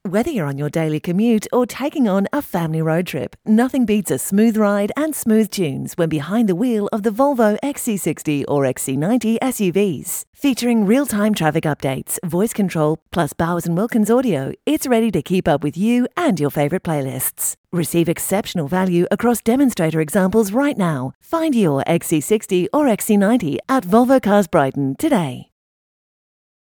Female
In my natural Australian accent I strike a friendly, intelligent, warm and energetic tone suitable for an upbeat commercial sound or a more sophisticated timbre for e-learning.
Radio Commercials
Australian Radio Spot
All our voice actors have professional broadcast quality recording studios.